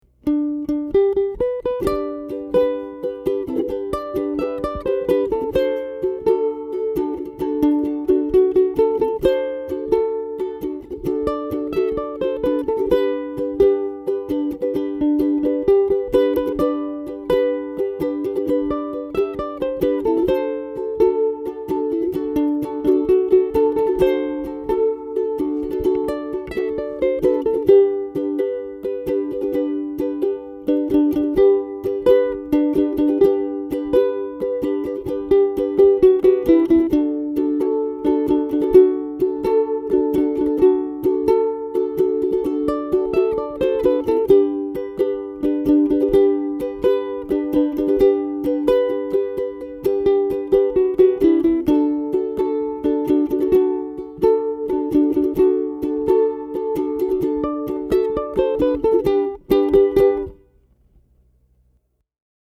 купил себе пьезу от кремоны